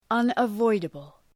Προφορά
{,ʌnə’vɔıdəbəl}